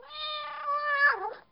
cat_meow.wav